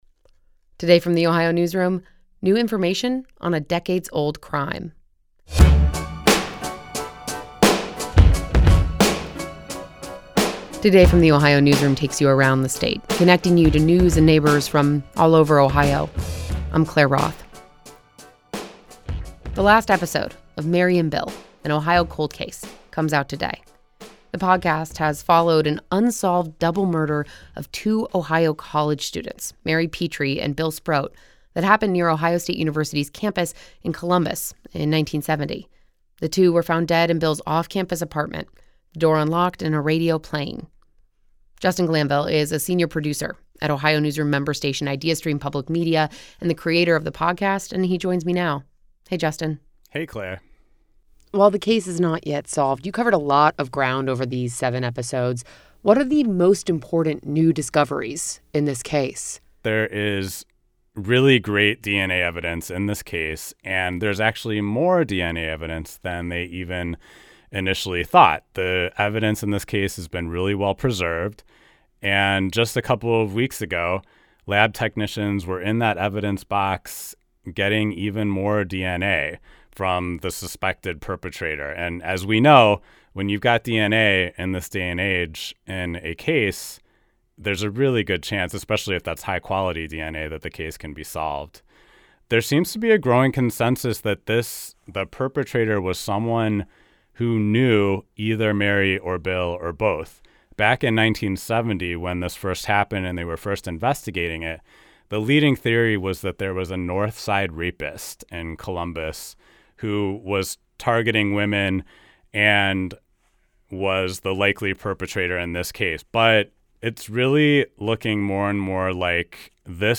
This conversation has been lightly edited for clarity and brevity.